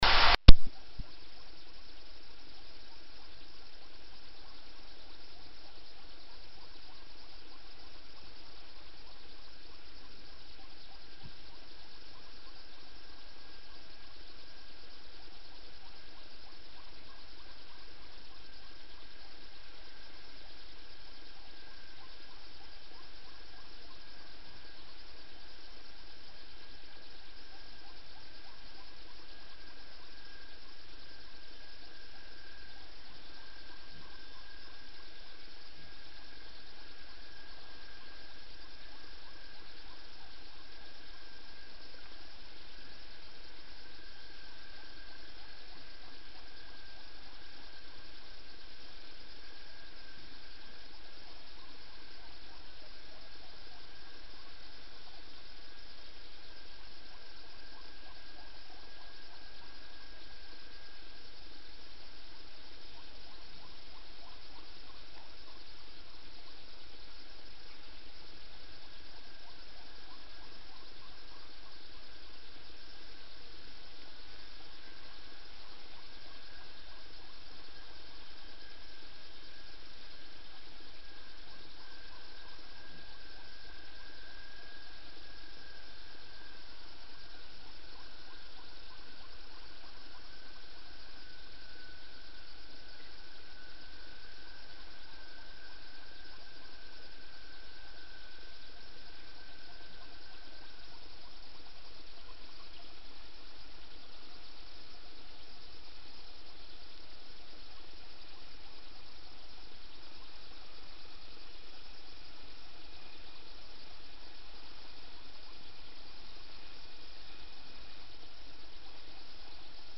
Delföredragning analys av ubåtsljud
Totalförsvarets forskningsinstitut (FOI) genomförde under fredagen delföredragning för Försvarsmakten rörande analysen av två inspelningar från ubåtsjakten i och utanför Hårsfjärden 1982.
Vidare har inspelningen av ett helt annat ljud, ljudet på 3.47 minuter, från den 12 oktober 1982, analyserats och fältförsök med referensinspelning har utförts. En preliminär slutsats från fältförsöken är att ytfartyg inte kan uteslutas.